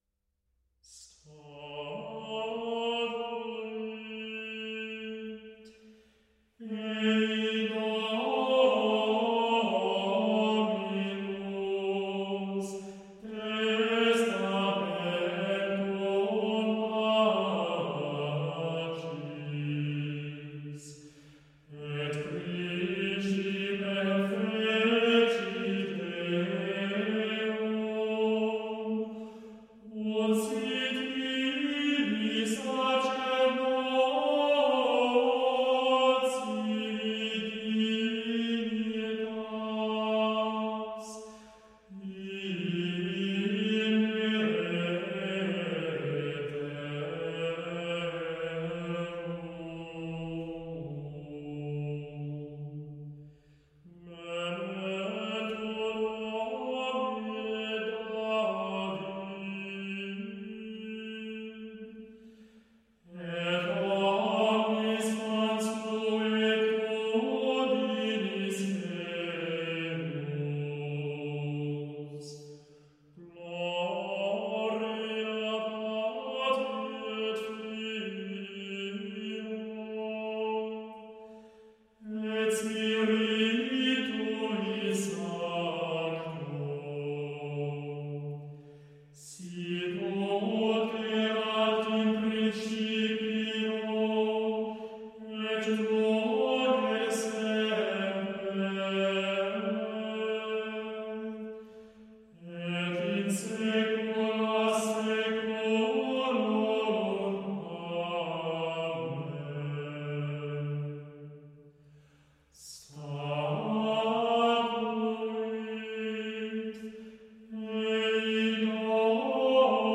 Mass
Group: Choir
Choral composition that sets the invariable portions of the Eucharistic liturgy.
Allegri - 02. Messe Vidi Turbam Magnam, a six voix - Introit- statuit ei dominum